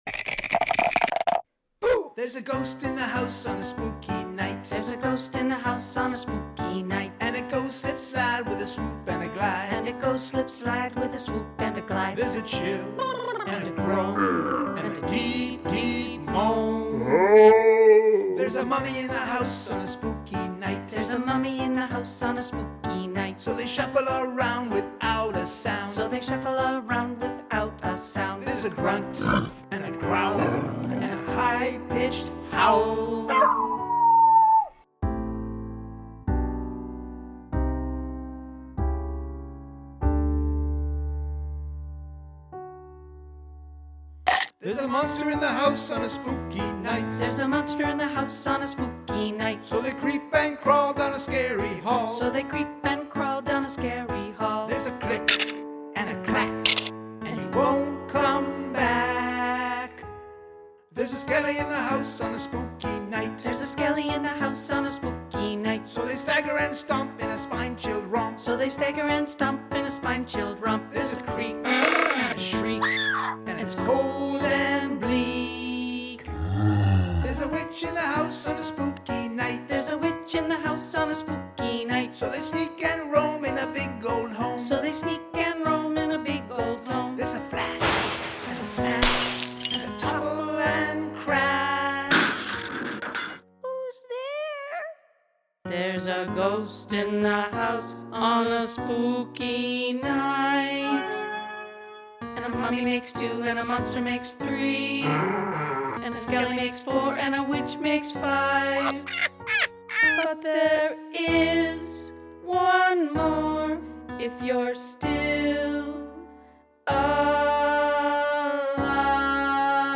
Ghost in the House – book review & tribute song